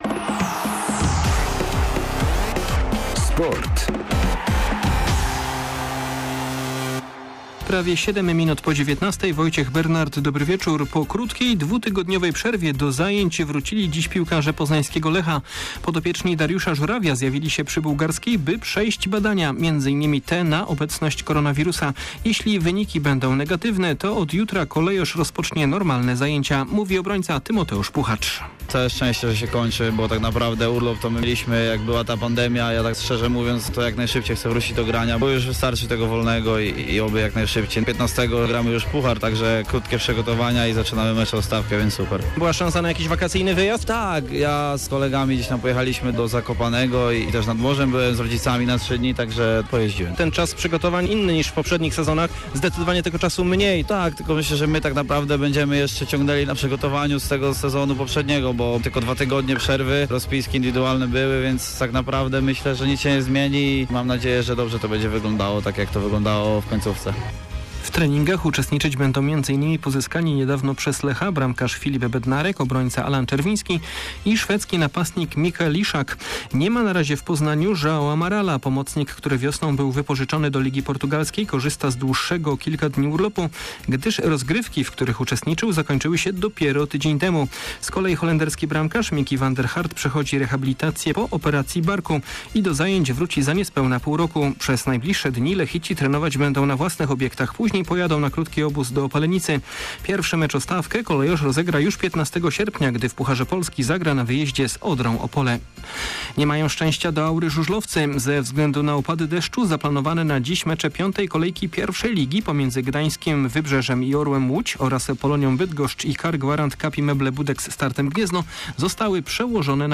03.08. SERWIS SPORTOWY GODZ. 19:05